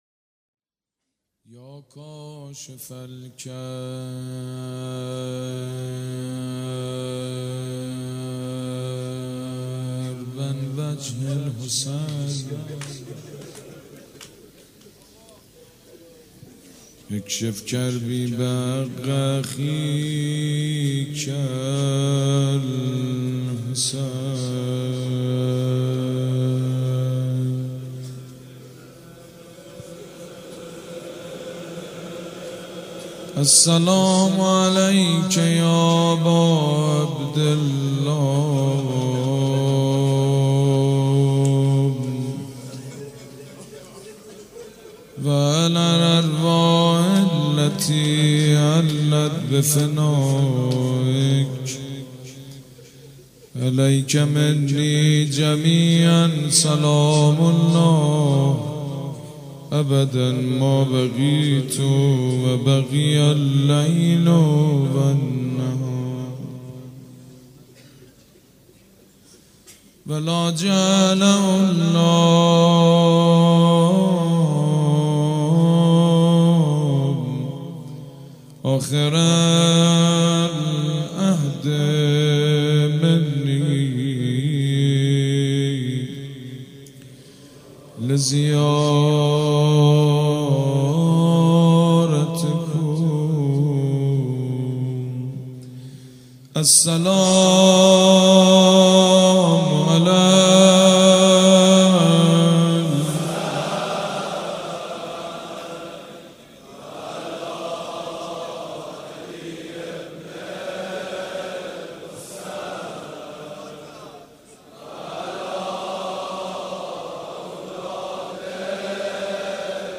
مراسم شب تاسوعای محرم الحرام سال 1395 با نوای سید مجید بنی فاطمه.